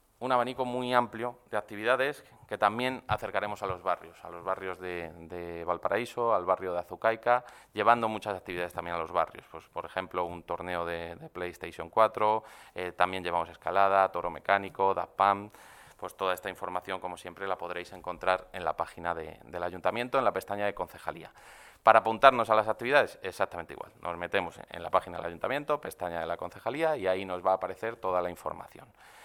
AUDIOS. Pablo García, concejal de Juventud